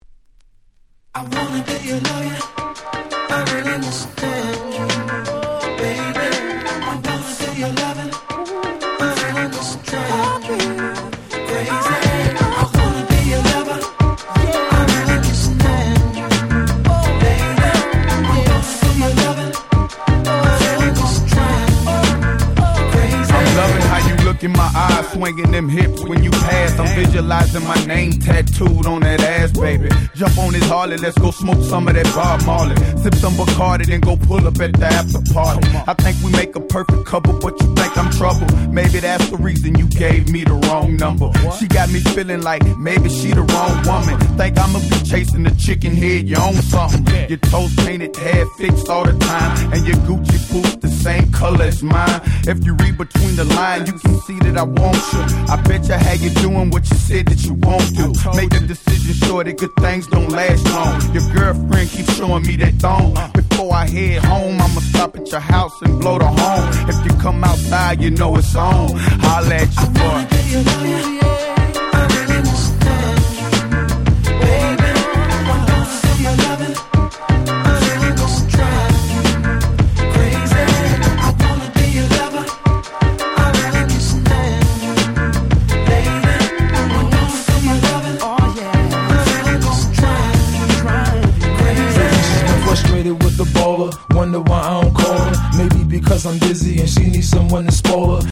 03' Big Hit Hip Hop !!
そのくらい大好きだし、間違い無く今でもバッチリ通用する00's Hip Hop Classicでしょう。